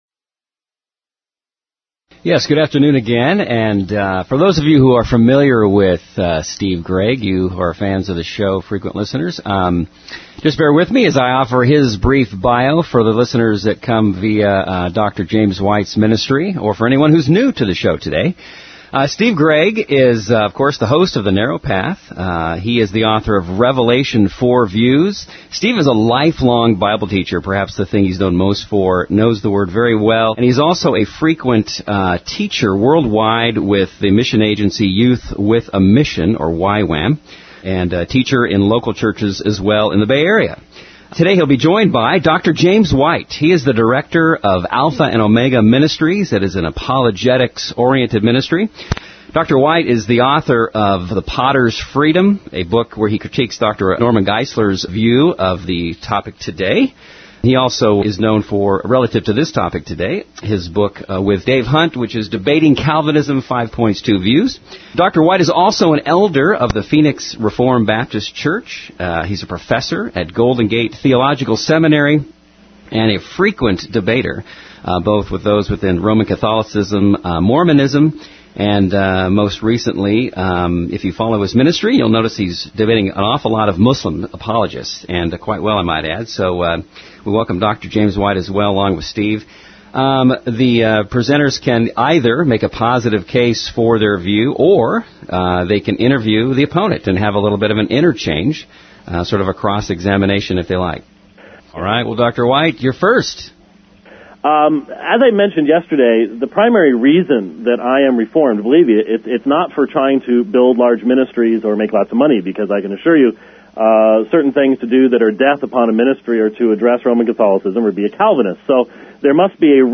Calvinism Debate